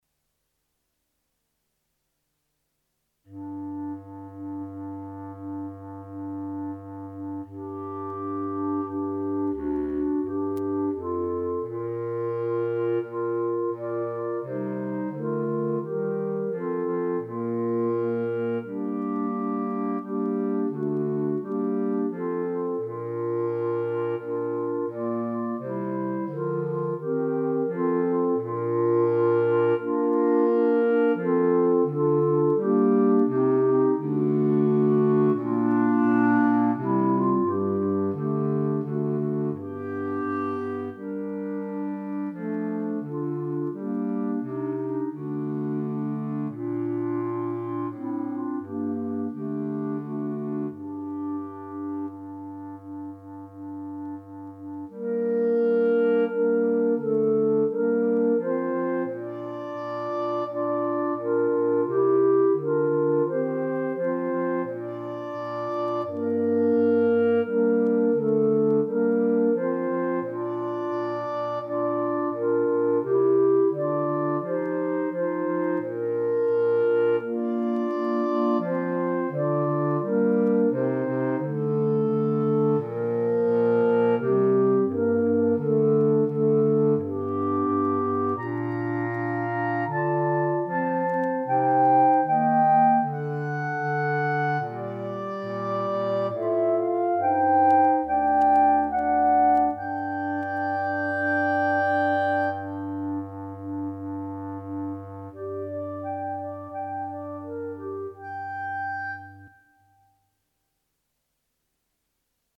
Instrumentation:3 Clarinet, Bass Cl.